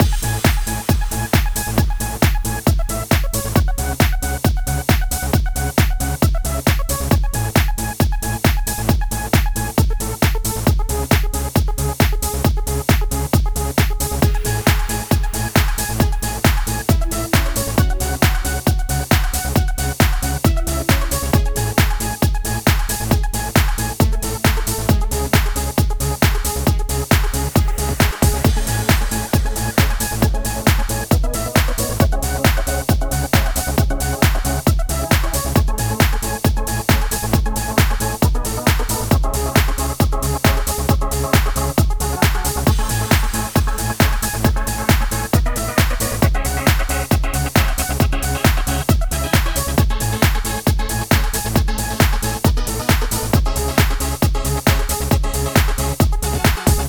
TI CK7 135 Full Mix.wav